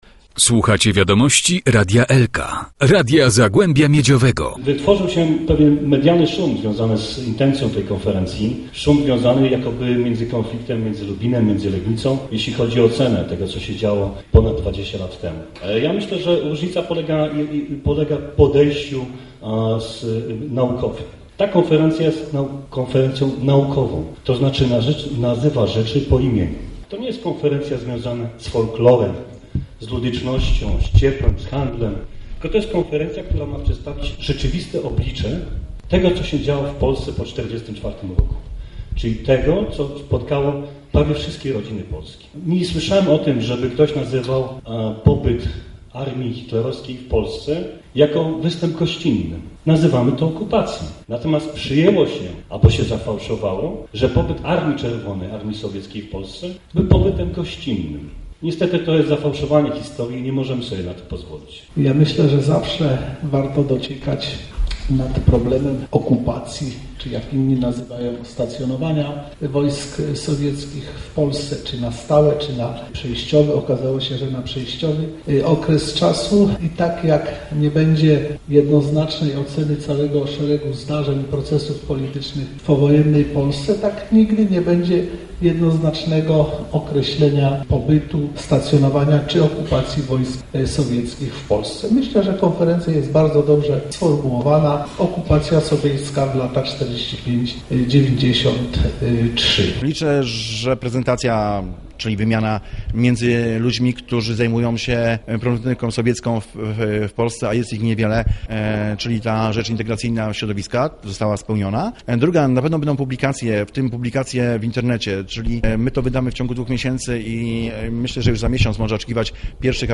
konferencjamuzalubinsowieci.jpgW Centrum Kultury "Muza" w Lubinie rozpoczęła się konferencja popularno - naukowa ph: "Okupacja sowiecka 1945 - 1993".
Konferencję uroczyście otworzył prezydent Lubina, Robert Raczyński.
konfmuzalubin.mp3